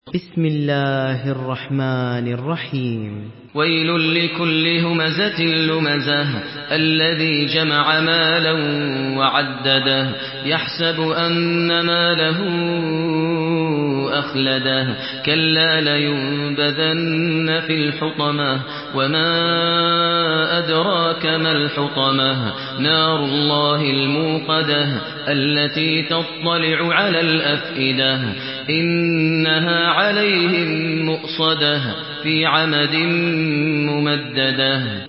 Surah الهمزه MP3 in the Voice of ماهر المعيقلي in حفص Narration
Listen and download the full recitation in MP3 format via direct and fast links in multiple qualities to your mobile phone.
مرتل حفص عن عاصم